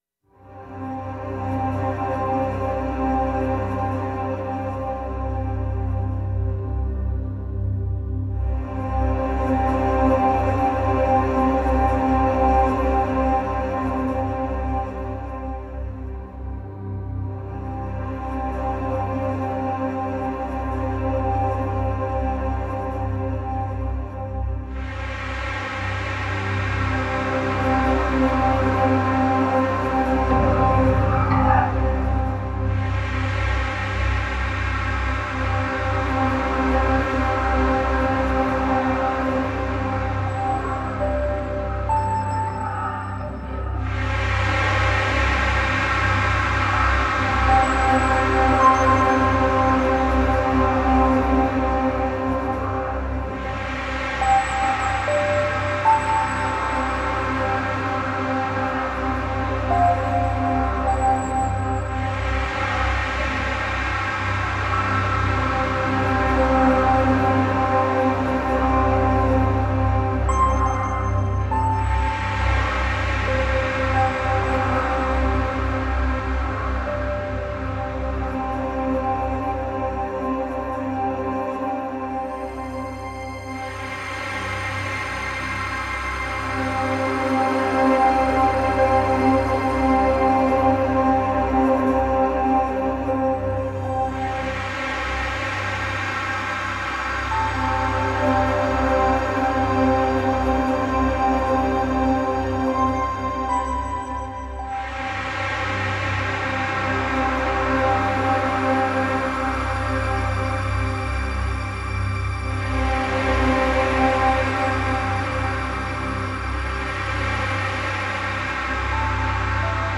New age Нью эйдж Музыка нью эйдж